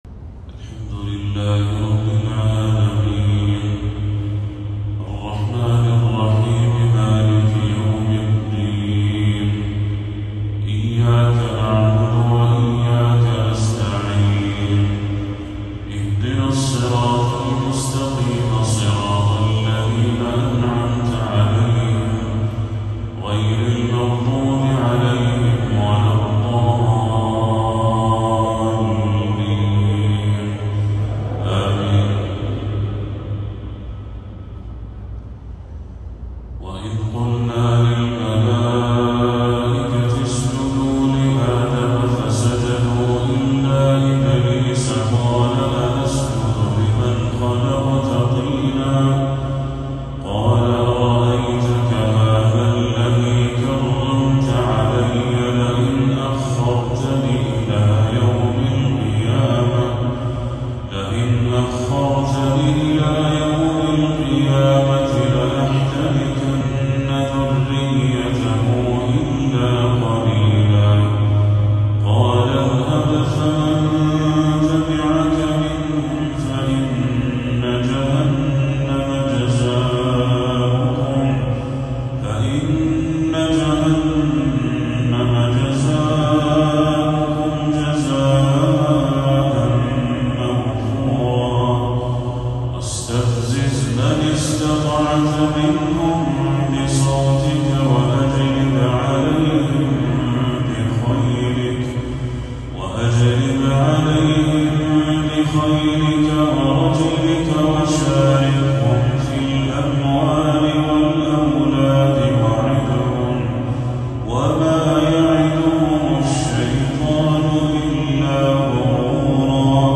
تلاوة بالغة الجمال من سورة الإسراء للشيخ بدر التركي | عشاء 10 ربيع الأول 1446هـ > 1446هـ > تلاوات الشيخ بدر التركي > المزيد - تلاوات الحرمين